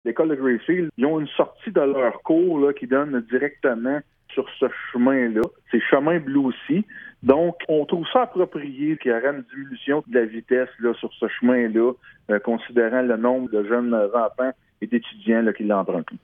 Le maire, Mathieu Caron, explique pourquoi il était important pour la Ville d’appuyer cette demande :